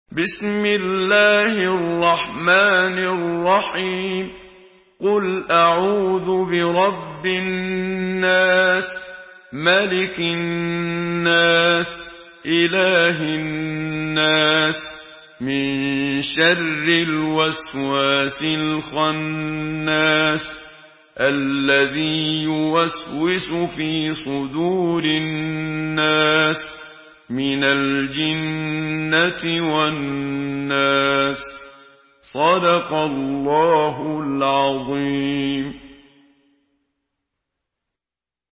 Surah An-Nas MP3 in the Voice of Muhammad Siddiq Minshawi in Hafs Narration
Murattal